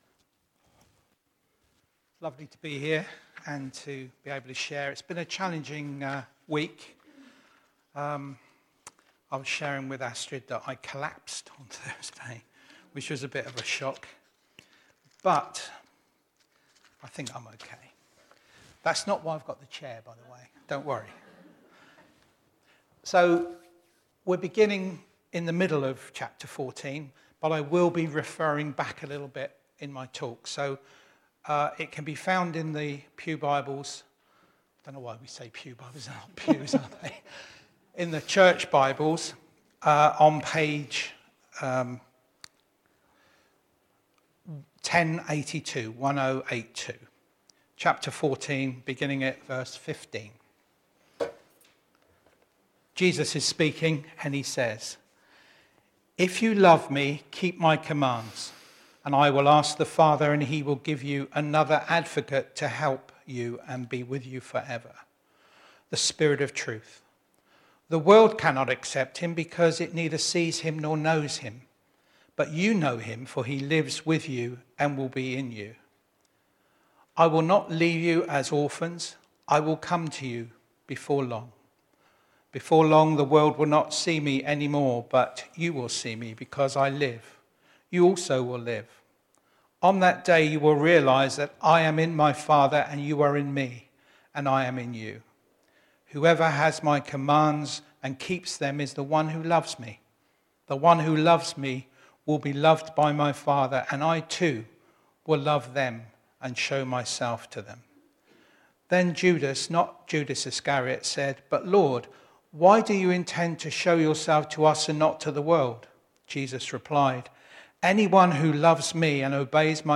Show or hear video or audio of talks or sermons from the Thornhill Baptist Church archives.